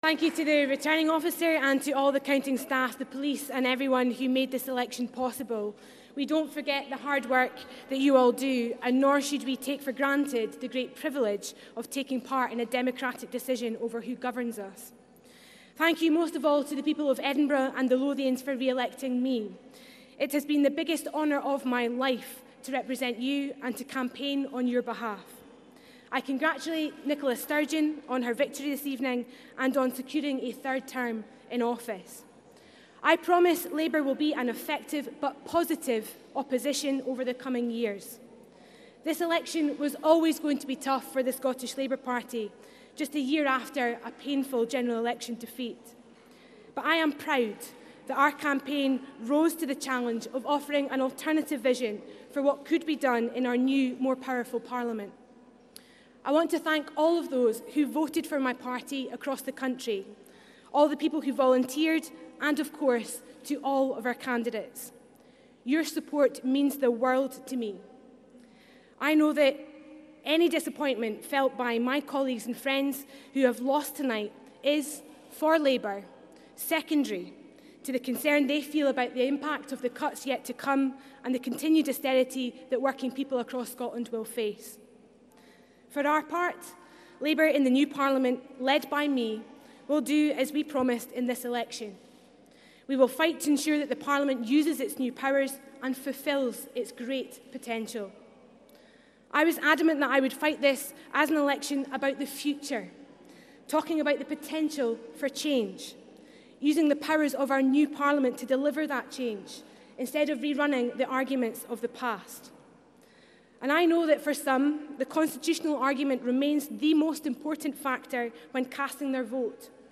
Kezia Dugdale's full concession speech - Election Live